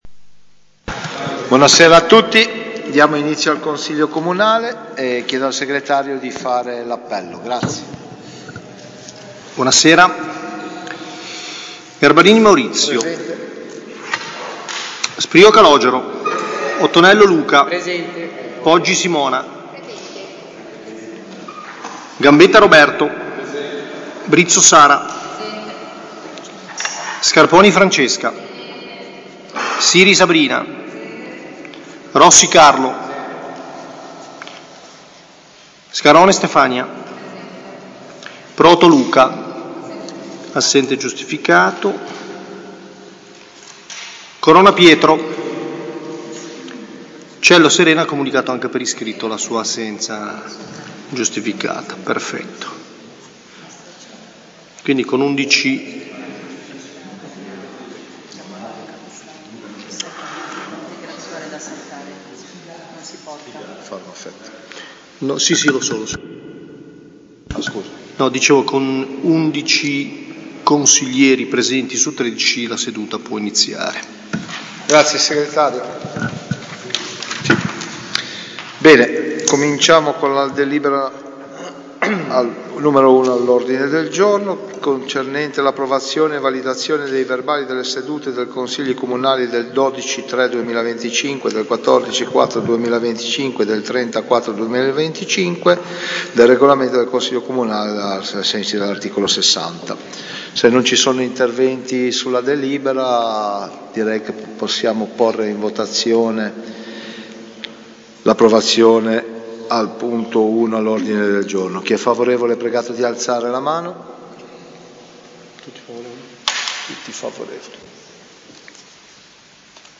Consiglio Comunale del 22 maggio 2025 - Comune di Albisola Superiore
Seduta del Consiglio comunale di giovedì 22 maggio 2025, alle 21.00, presso l’Auditorium comunale, in seduta ordinaria, sono stati trattati i seguenti argomenti: Approvazione e validazione dei verbali delle sedute dei Consigli Comunali del 12.03.2025, del 10.04.2025, del 30.04.2025 ai sensi dell’art. 60 del regolamento del Consiglio comunale.